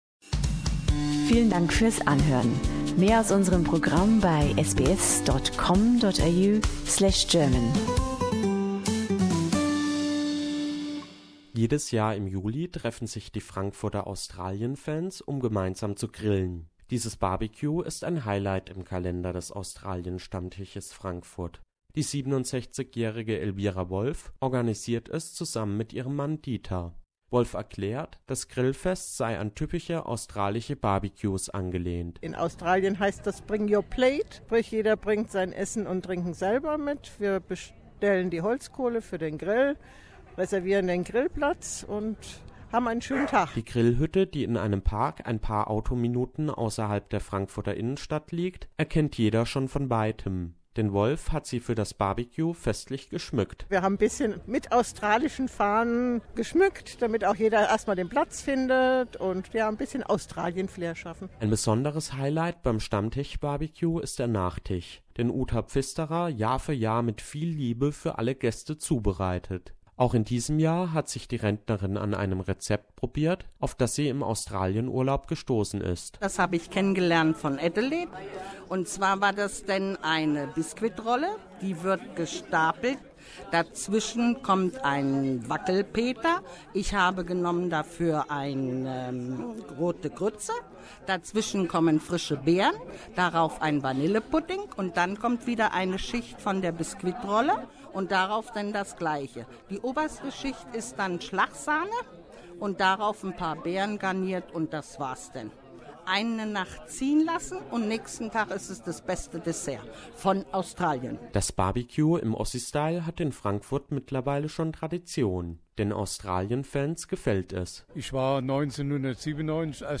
Fröhliche Runde: Am vorletzten Juli-Wochenende trafen sich die Australienfans in einem Park in Klesterbach zum BBQ Source